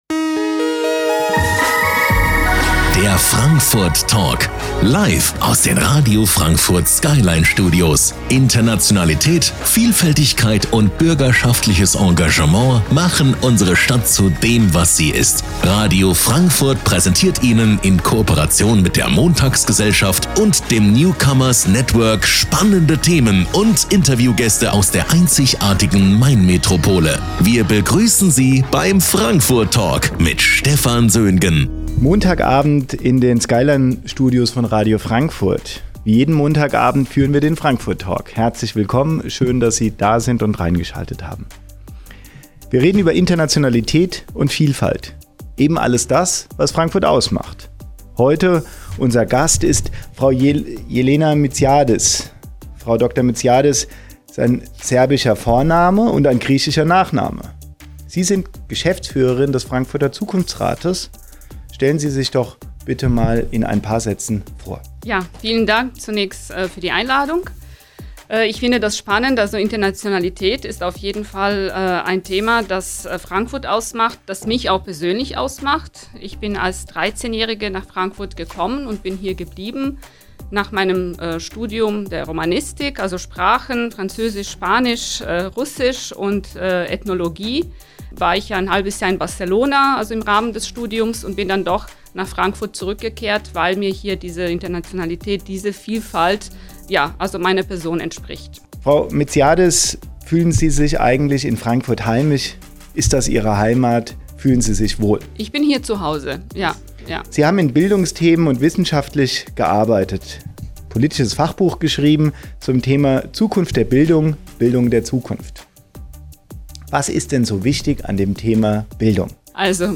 Interview beim Radio Frankfurt